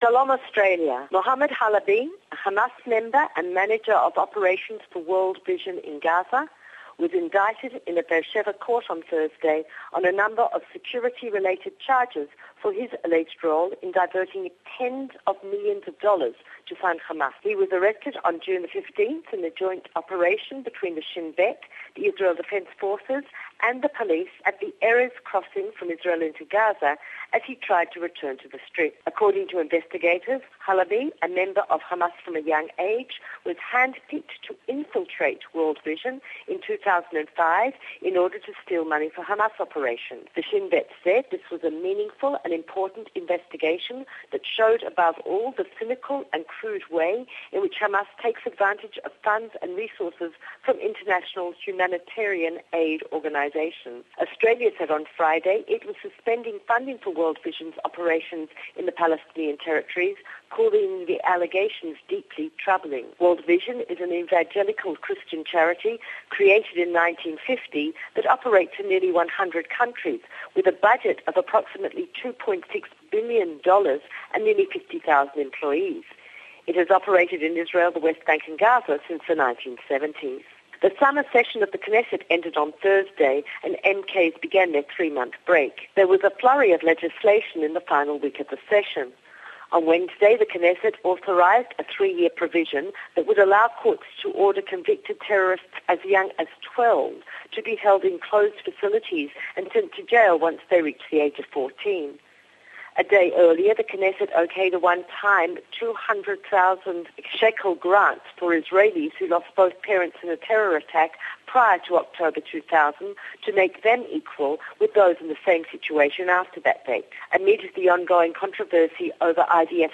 English Report